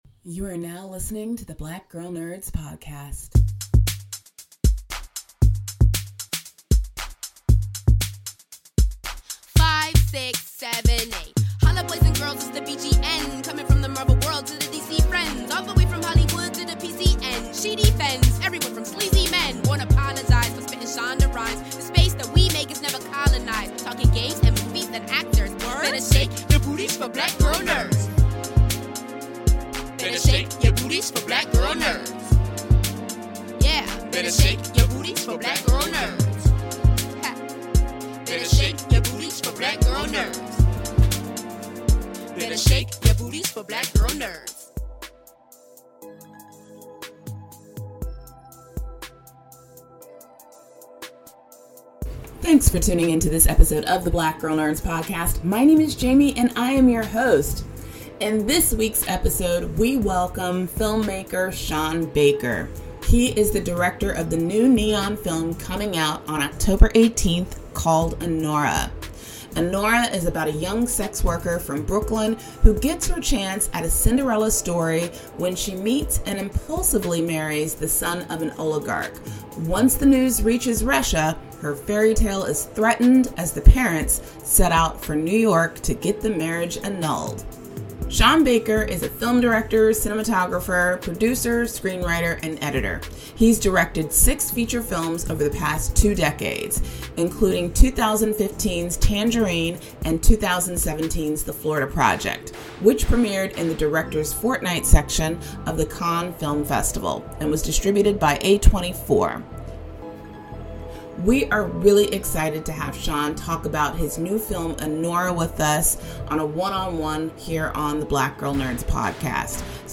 In this week's episode of the Black Girl Nerds podcast, we welcome filmmaker Sean Baker.